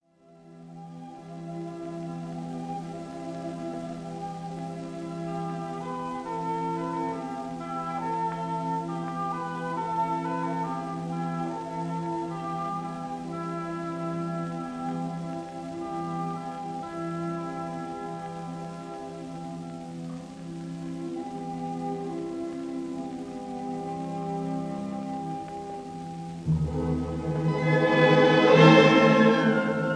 This recording made in World War Two
tone poem